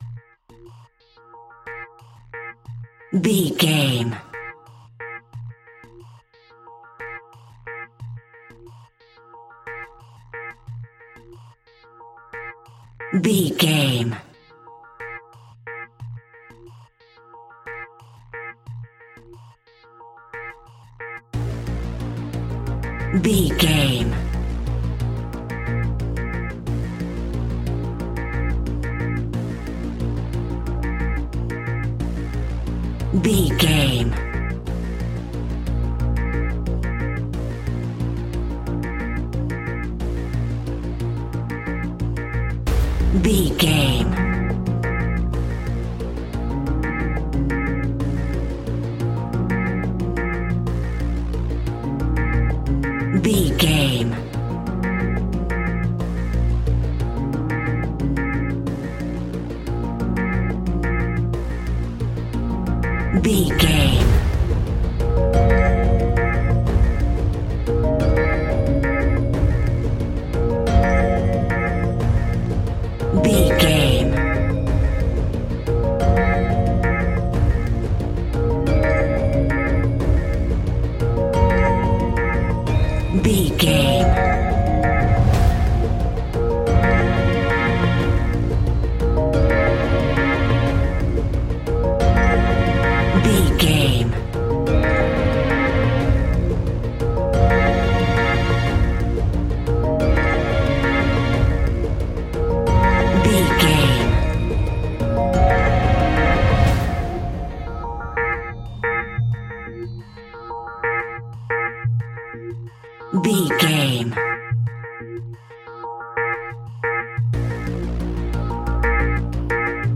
Scary Background Industrial Music Full.
Aeolian/Minor
A♭
ominous
dark
eerie
synthesizer
strings
horror music